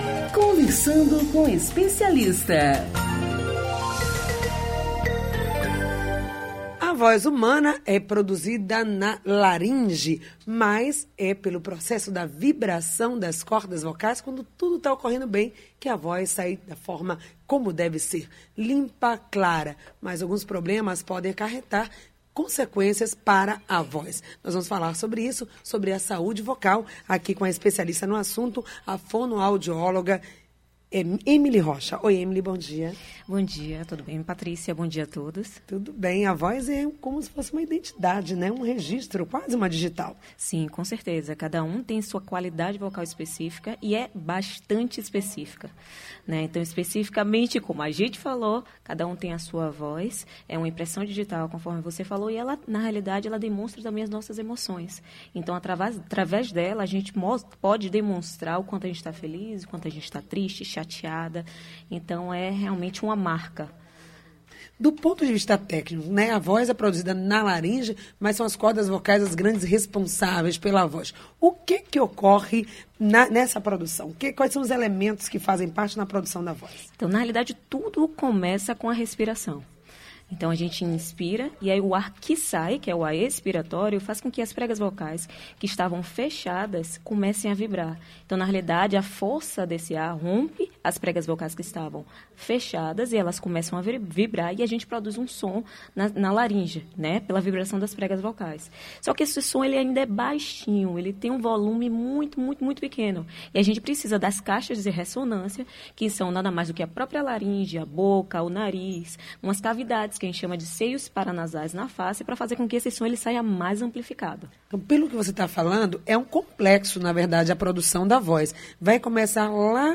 Se as patologias não forem corretamente tratadas, os pacientes podem perder a voz de forma definitiva. Saiba mais sobre o assunto, ouvindo a entrevista.